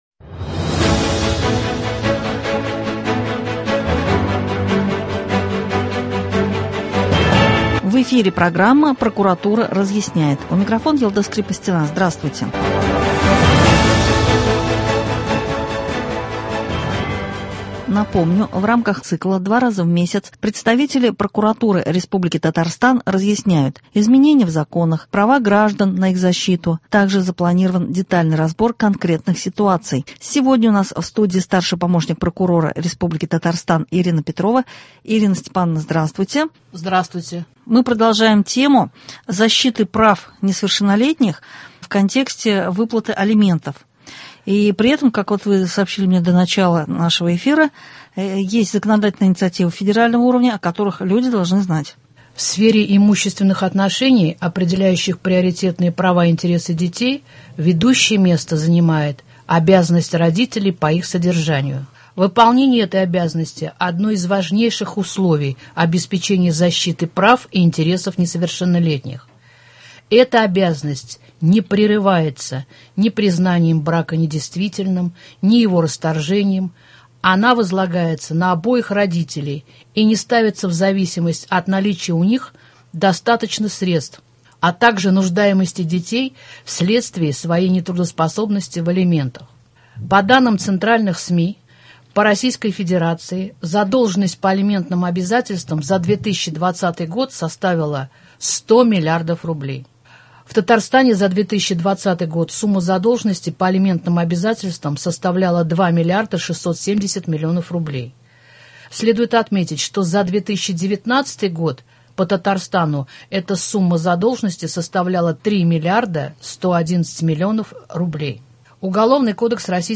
Два раза в месяц представители прокуратуры республики Татарстан разъясняют: изменения в законах, права граждан на их защиту, также запланирован детальный разбор конкретных ситуаций. В студии